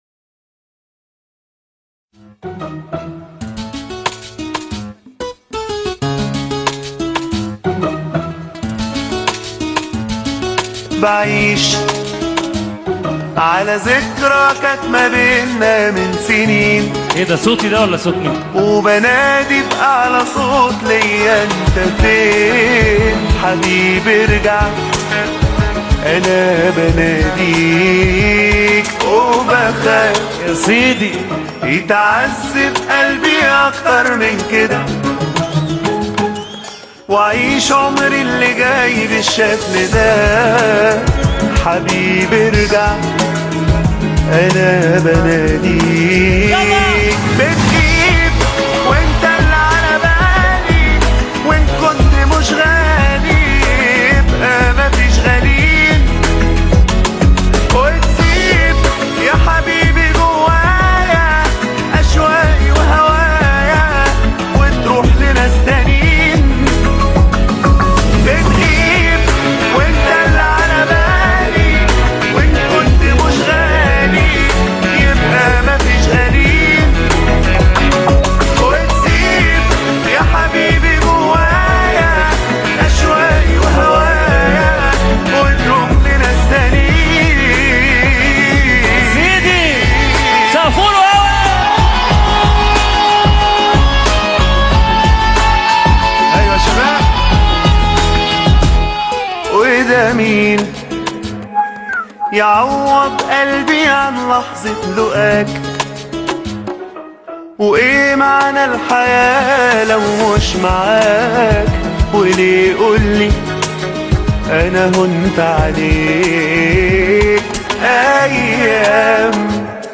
حفلة